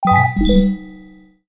07477 system confirm ding
confirm ding notification ready set sfx sound system sound effect free sound royalty free Sound Effects